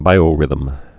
(bīō-rĭthəm)